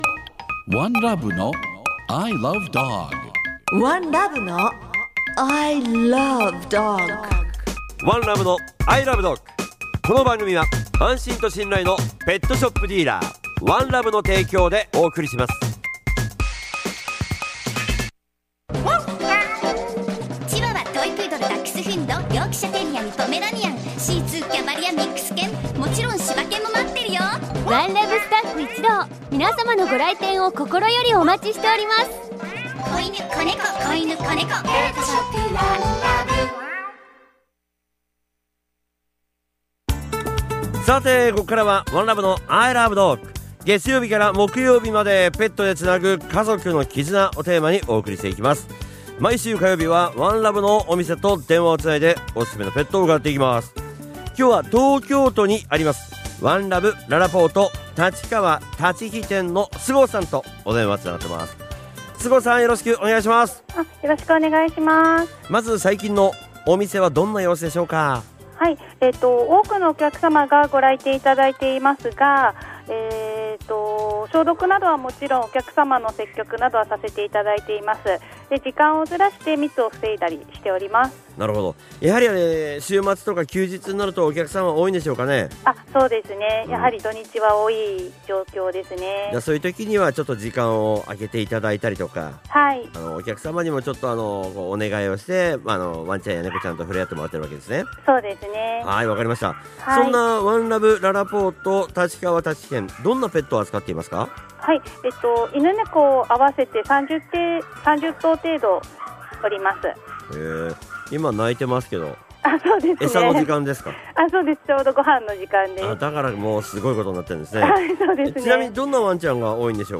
月曜は、街角突撃インタビューが聞けるワン！